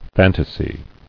[fan·ta·sy]